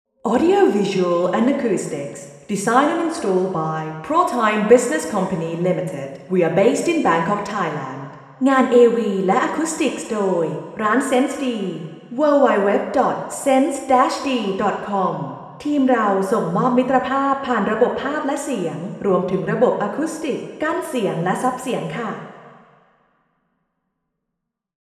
Room: Vethes Samosorn, Ministry of Foreign Affairs
Microphone: Omni
Reference Position: 2 m